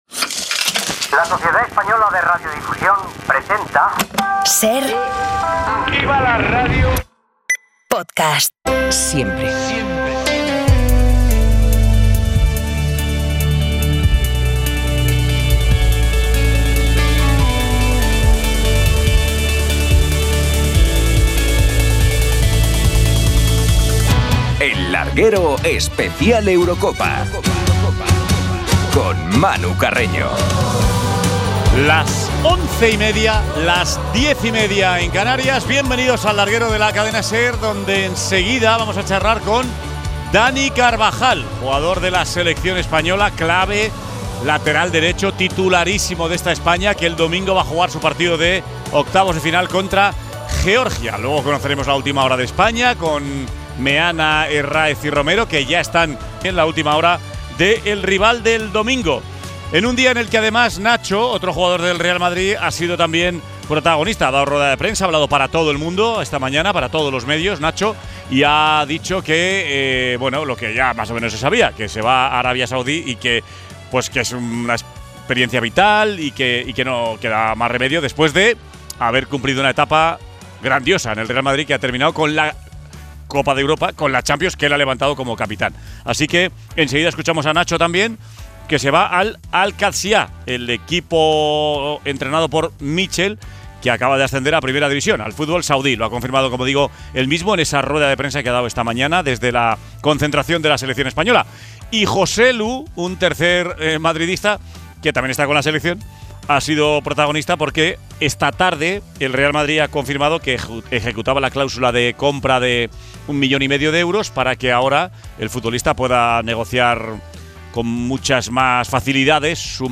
Entrevista a Dani Carvajal desde la concentración de la Selección